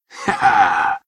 laughter